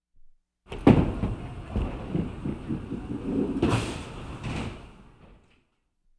Bowling - Gutter Ball.wav